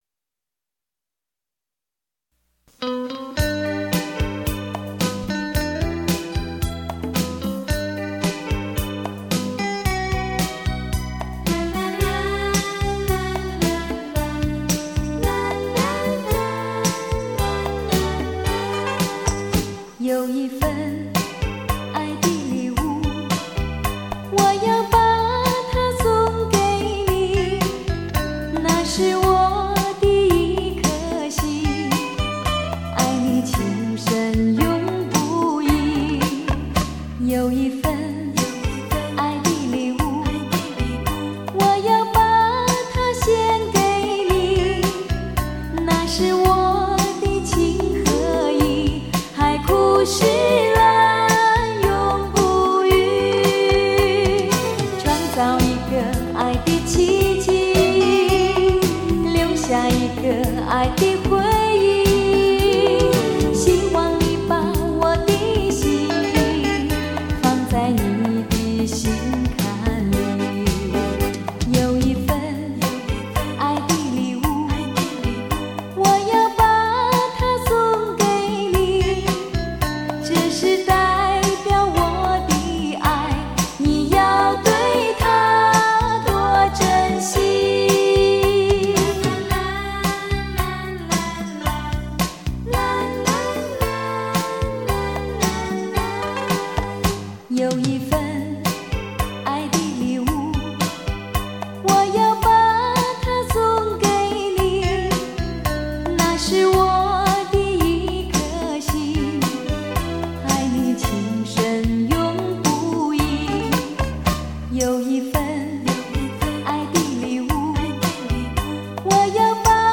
国语情歌